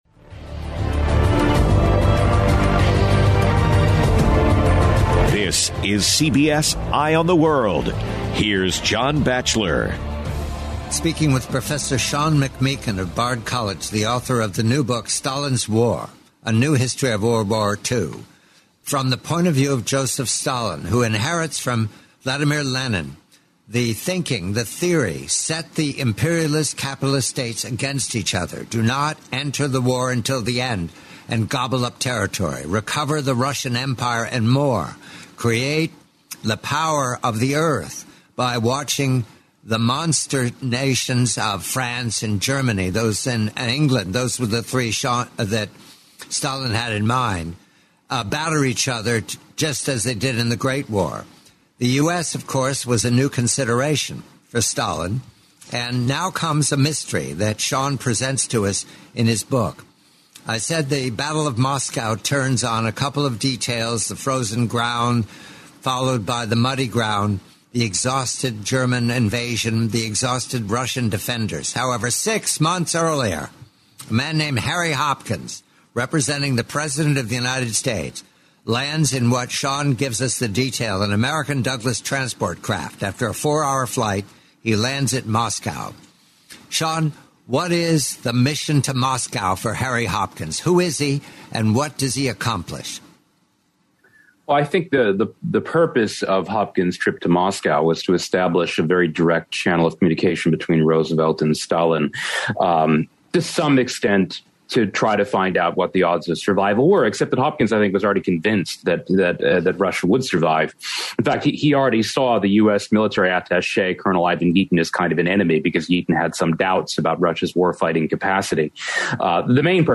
Audible Audiobook – Unabridged